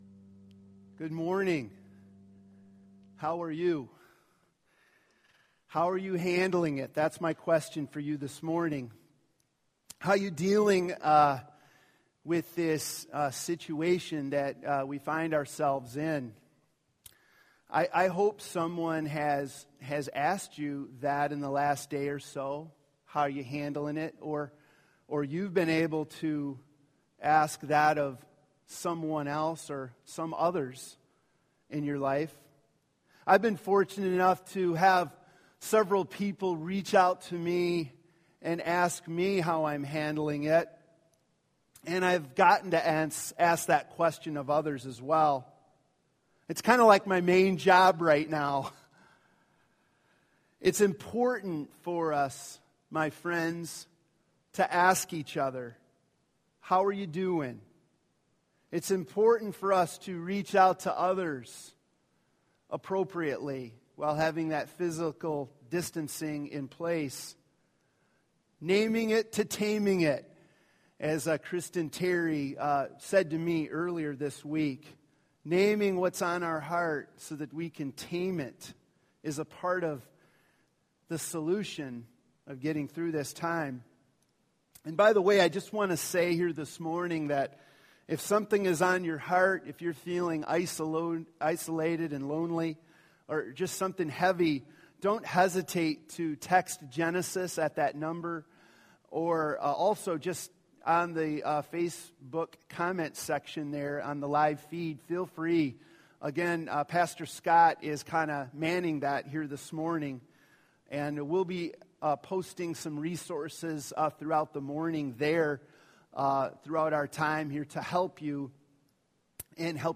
Service Type: Sunday Morning Preacher